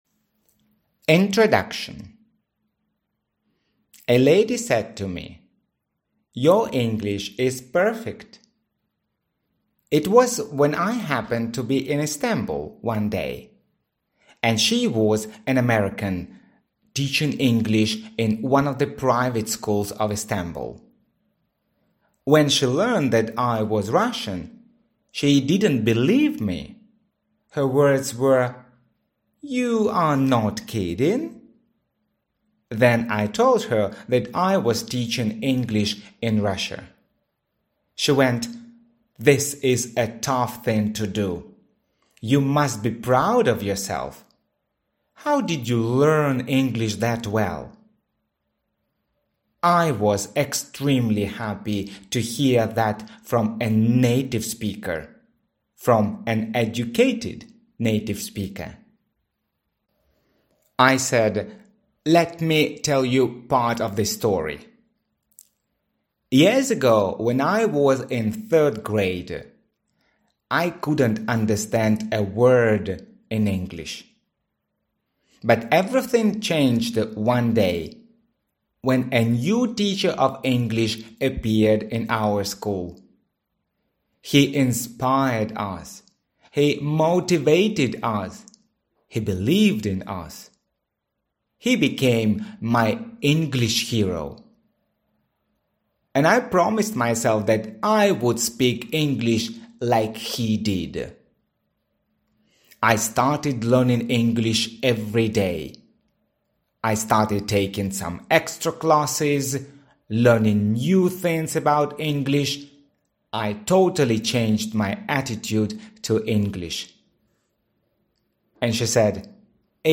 Аудиокнига Boost up your English | Библиотека аудиокниг